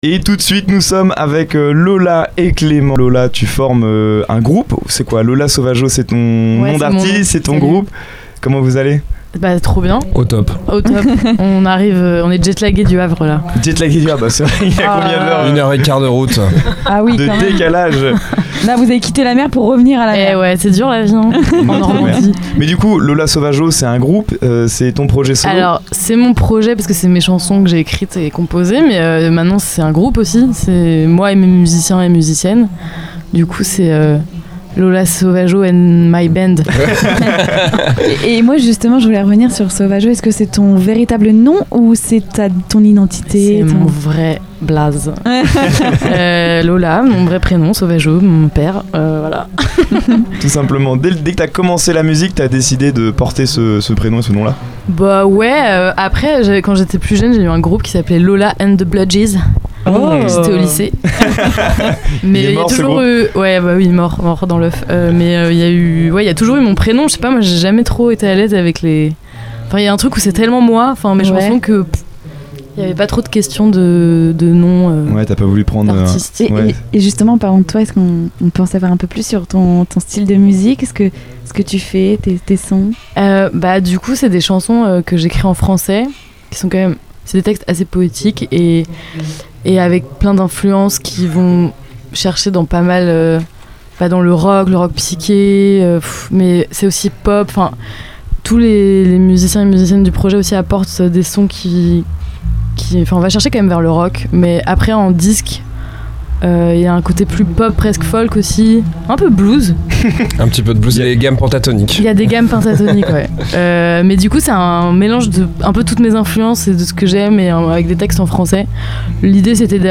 Dans cet épisode, la Mystery Machine fait escale à Luc-sur-Mer à l’occasion de l’événement Aérolive, organisé par la TFT.